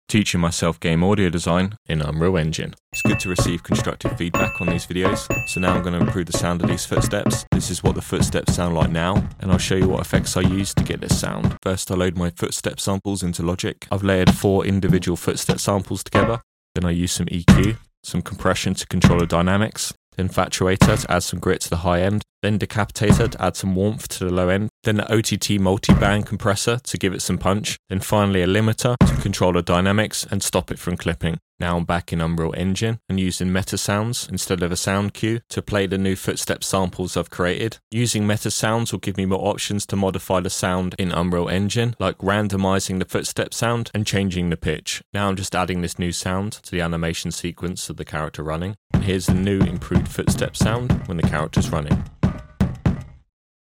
# 6 Teaching myself game audio design in Unreal Engine. Nu Metal Footsteps.